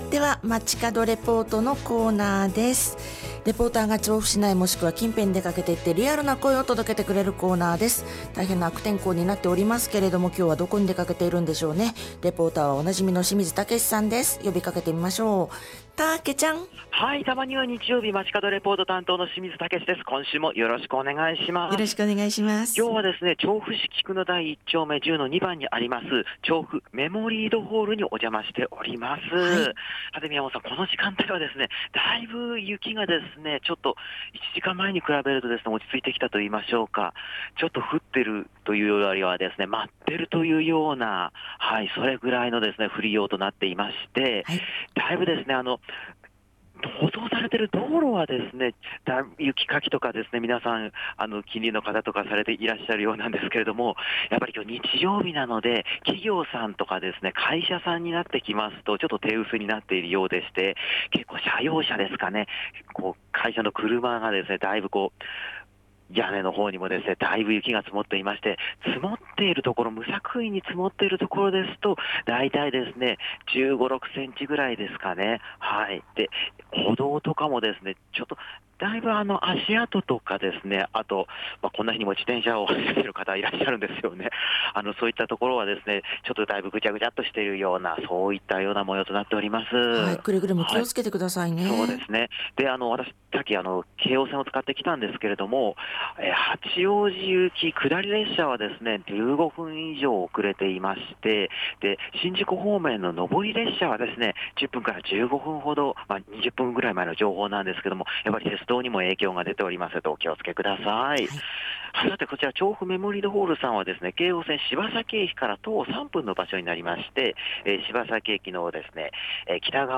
近年まれにみる積雪の日にお届けした本日の街角レポートは、 京王線・柴崎駅から徒歩3分、調布メモリードホールで開催中の 「手作り市」の会場からのレポートです！！